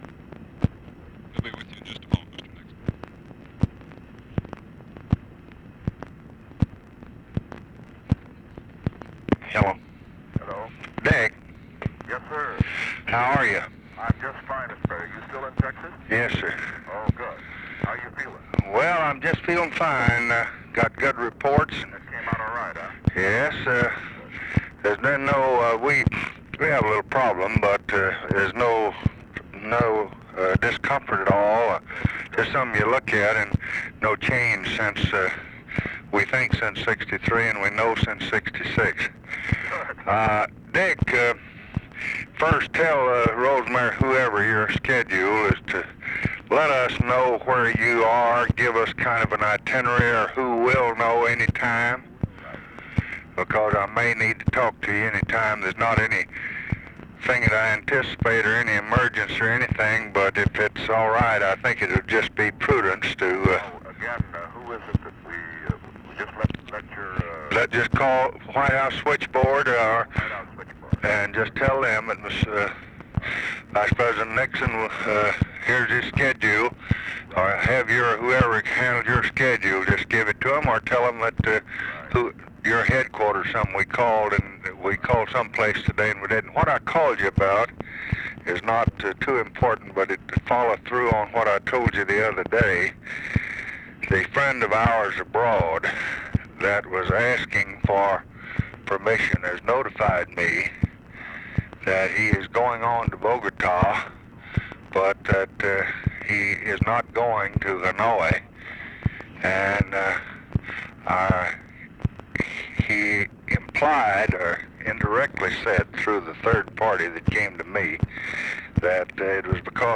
Conversation with RICHARD NIXON, August 18, 1968
Secret White House Tapes